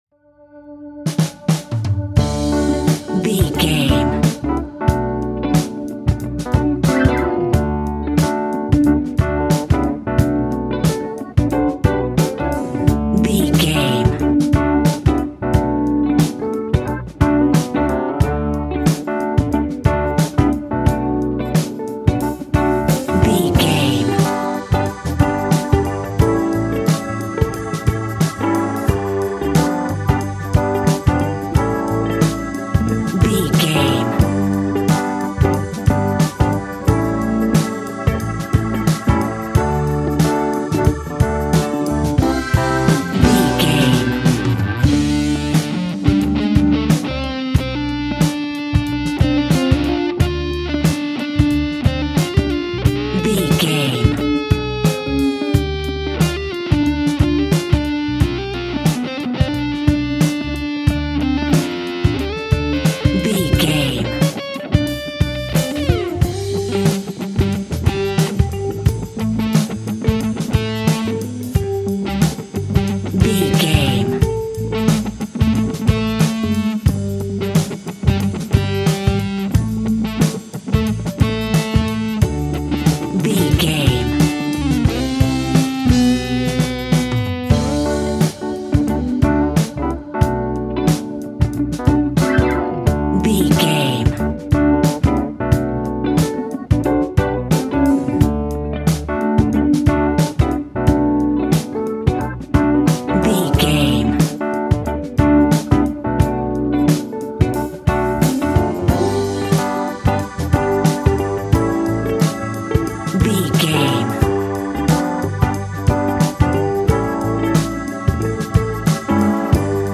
Ionian/Major
funky
uplifting
bass guitar
electric guitar
organ
drums
saxophone
groovy